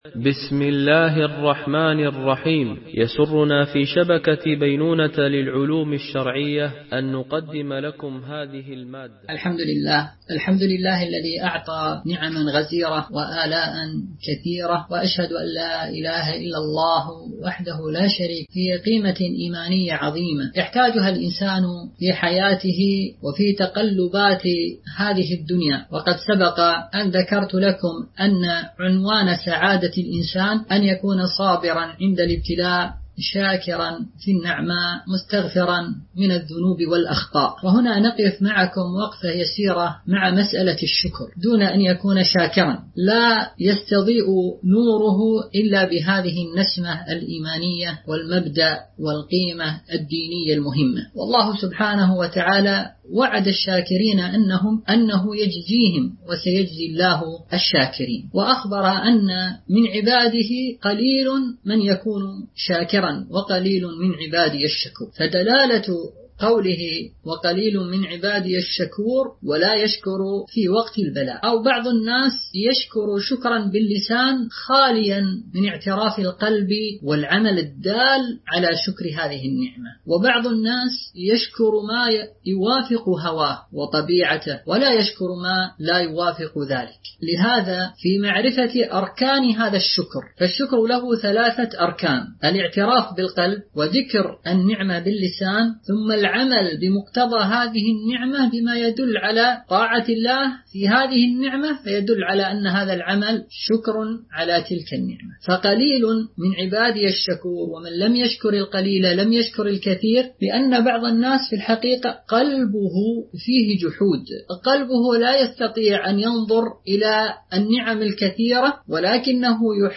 محاضرات